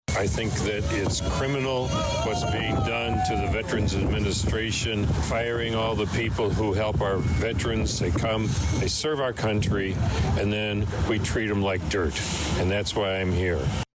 It began at 11 AM, on Main Street in front of VA Illiana Health Care; with a crowd of already of 70, and the numbers kept increasing during Saturday morning’s HANDS OFF rally in Danville; one of many across the country that were put together to protest the actions of Elon Musk and the Trump Administration.